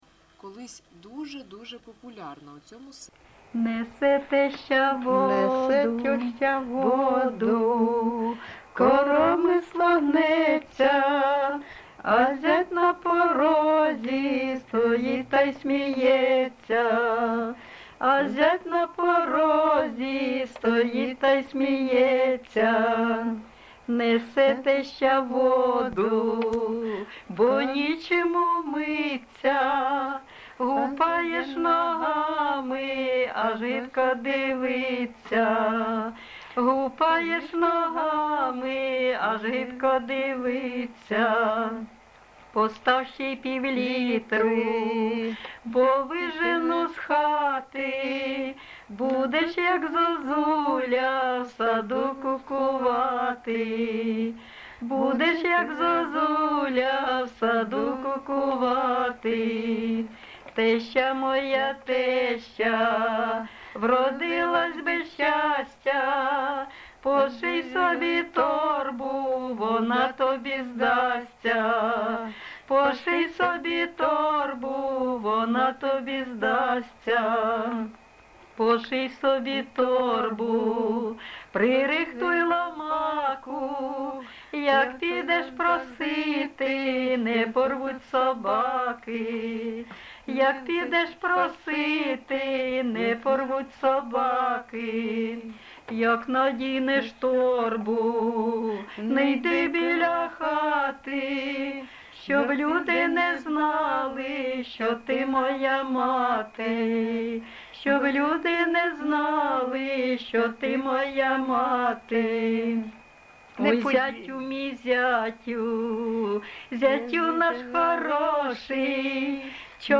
GenrePersonal and Family Life, Neo-Traditional Folk
Recording locationYasna Poliana, Kramatorskyi district, Donetsk obl., Ukraine, Sloboda Ukraine